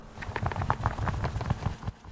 杂项 " 鸟翼48赫兹
标签： 跳动 跳动翼 翅膀 扑翼 鸟类
声道立体声